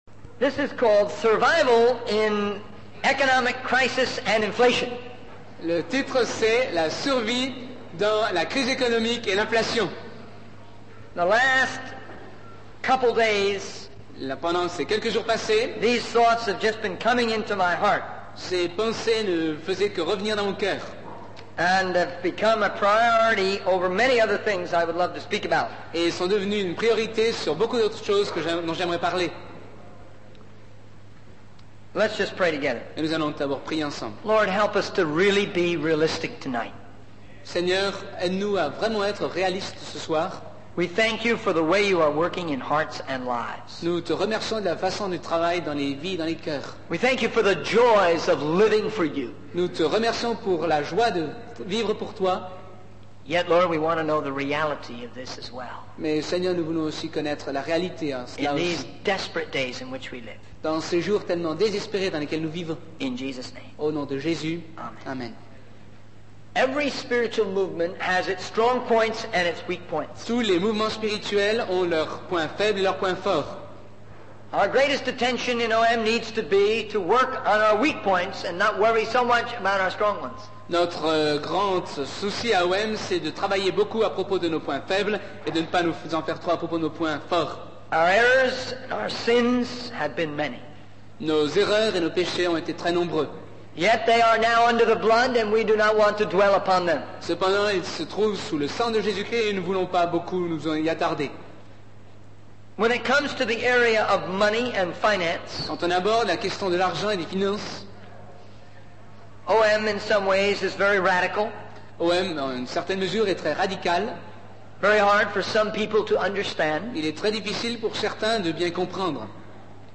In this sermon, the speaker discusses the importance of counting the cost before engaging in battle. He highlights the potential negative influence of television, particularly in regards to the increasing presence of explicit content.